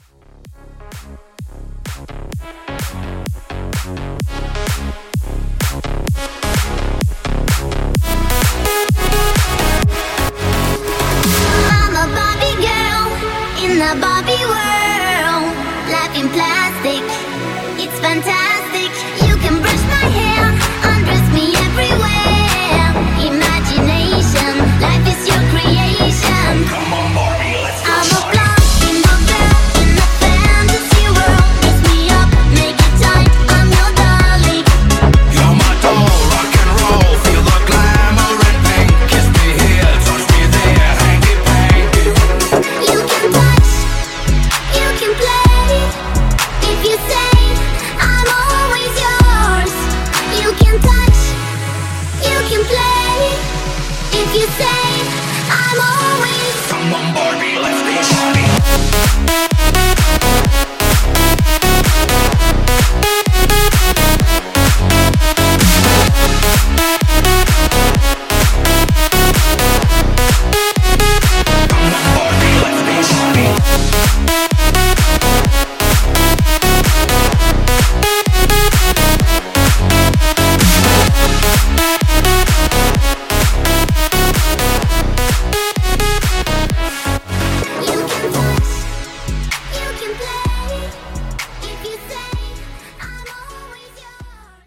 BPM: 128 Time